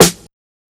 SNARE ATTACK.wav